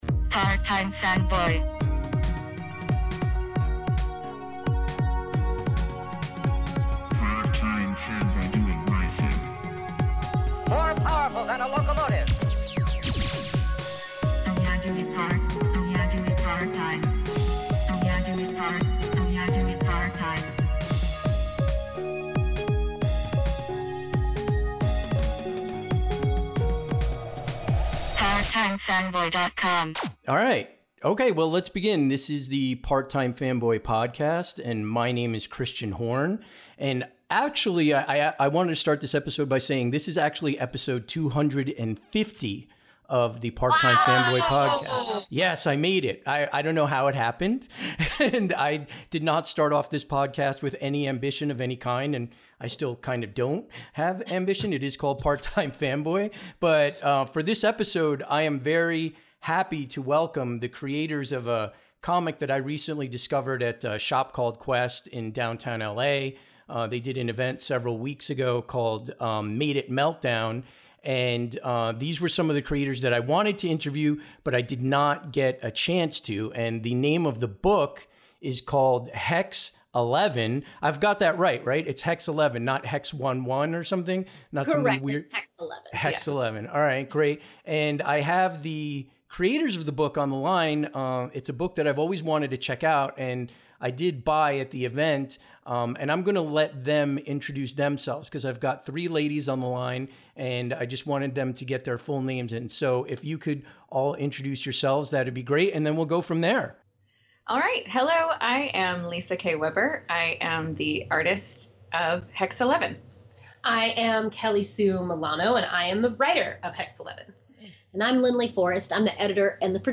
It’s an interview that all creators of indie comics will want and need to listen to!